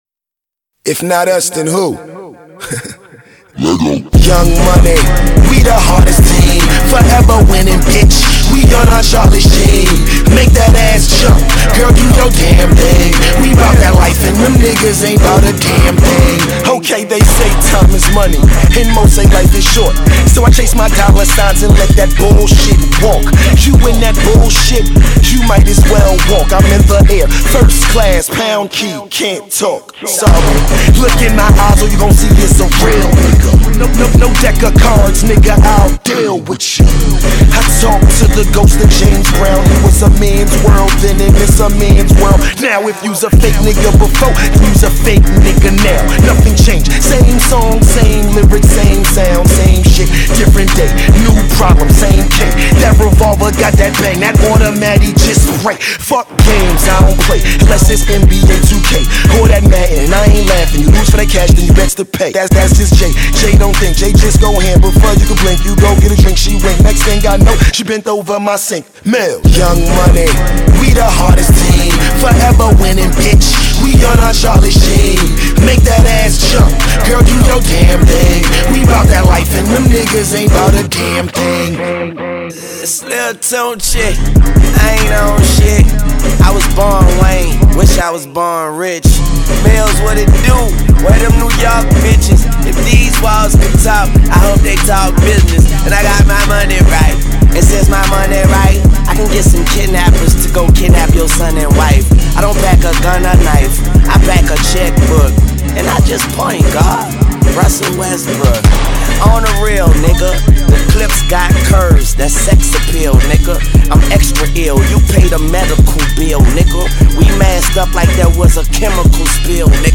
Hip Hop, Music, Rap